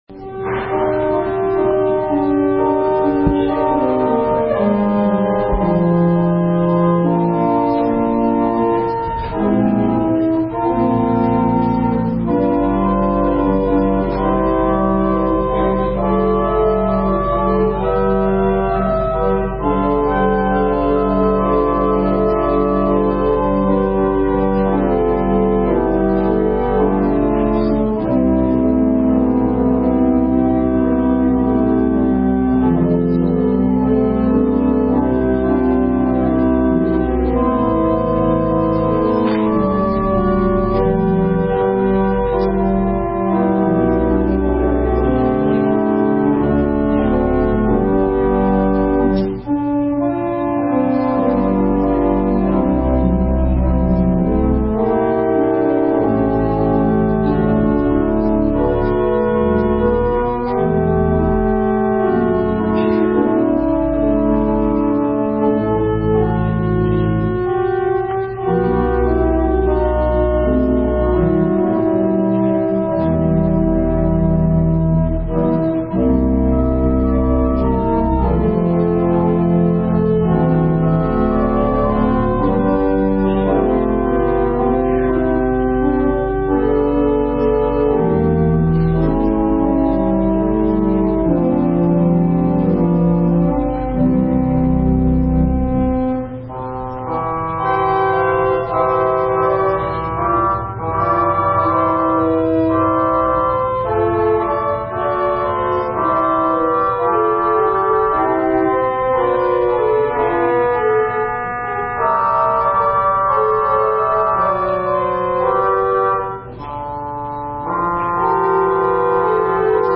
November 11, 2018 Worship Service